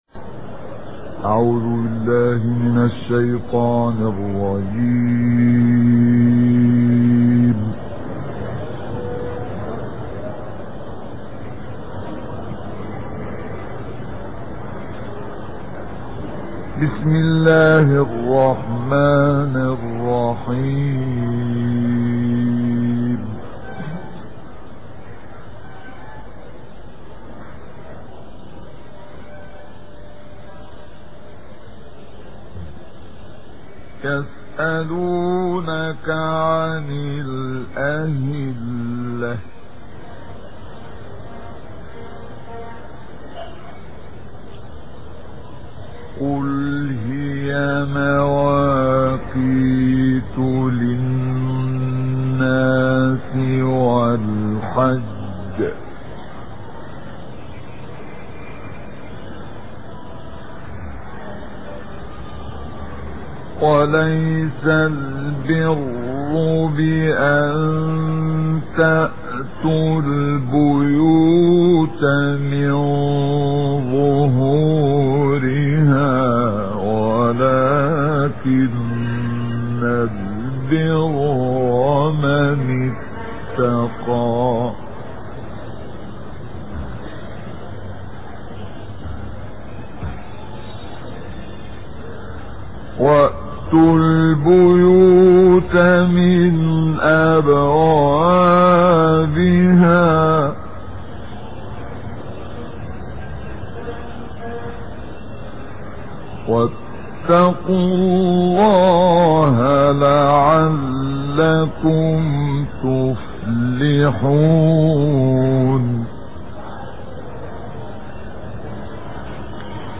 تلاوات مجودة نادرة للشيخ عبد الفتاح الشعشاعى